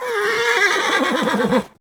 combat / creatures / horse
taunt1.wav